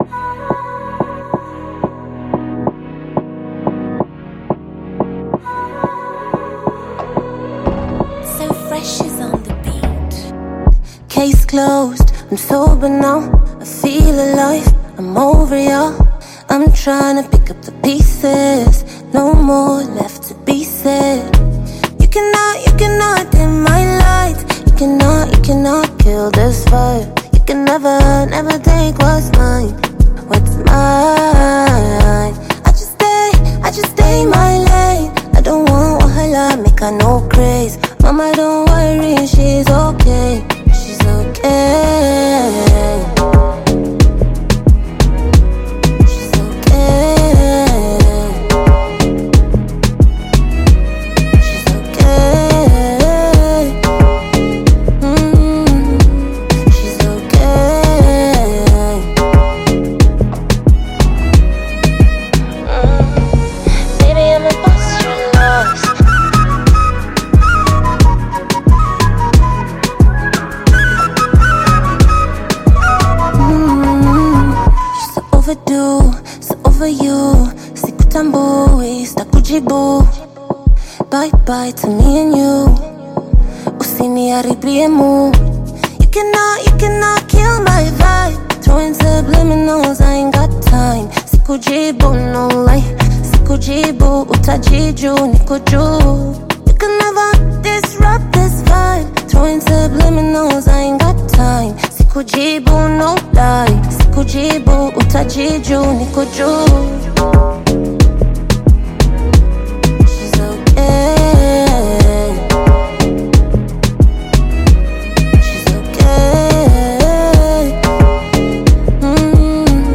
Kenyan artist and singer